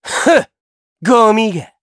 Neraxis-Vox_Victory_jp.wav